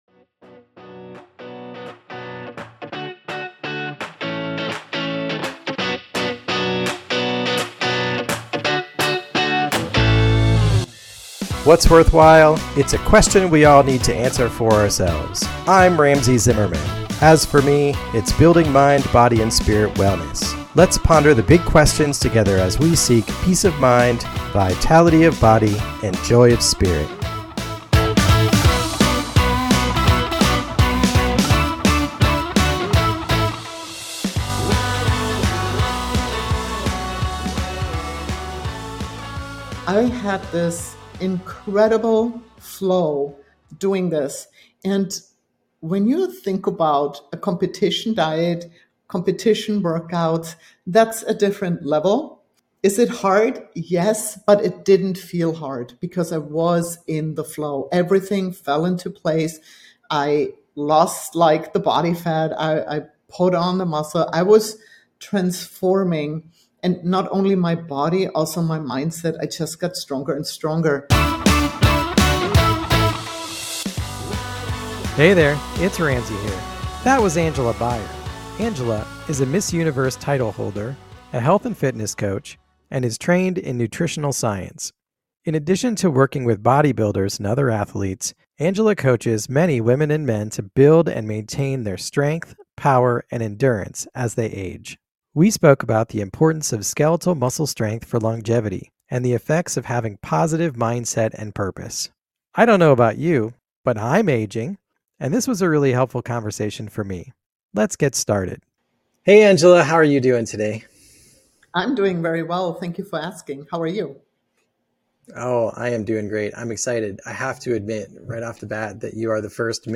Discussion